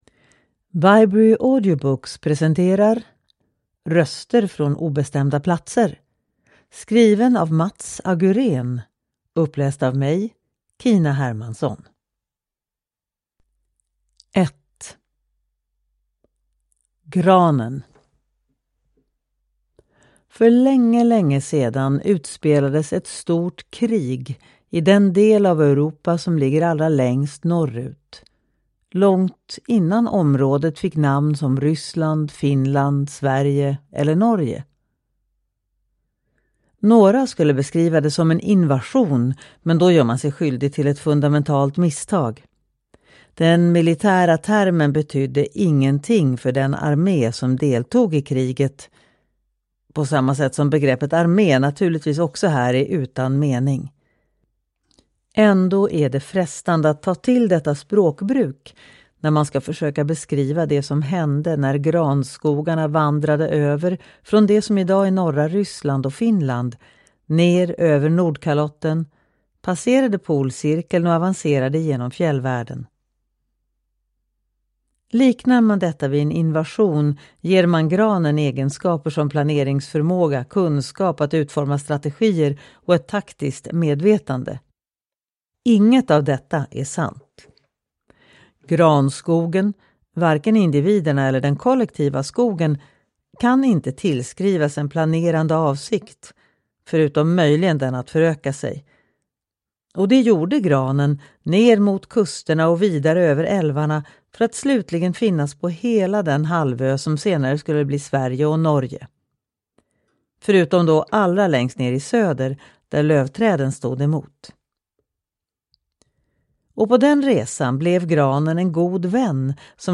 Röster från obestämda platser (ljudbok